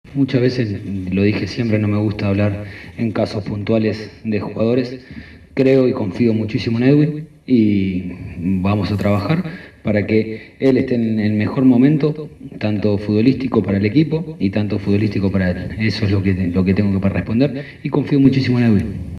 Fernando Gago - Dt Racing